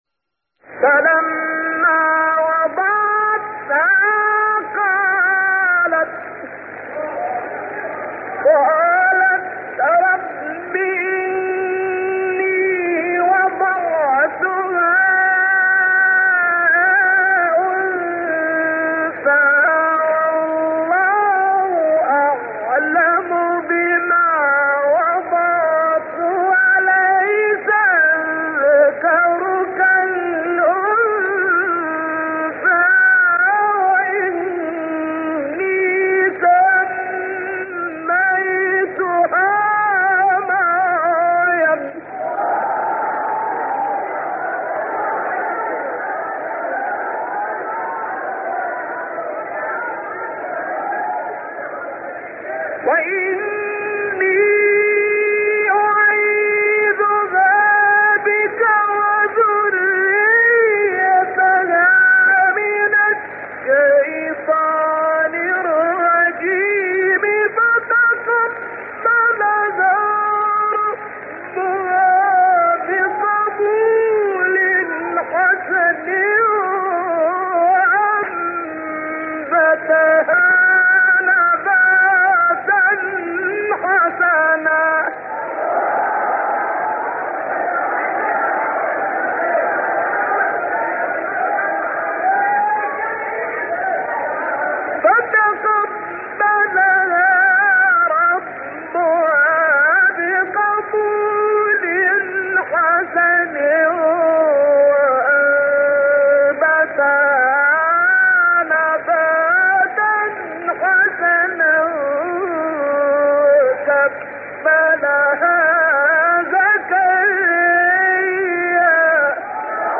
گروه فعالیت‌های قرآنی: مقاطع صوتی دلنشین از قراء بین‌المللی جهان اسلام را می‌شنوید.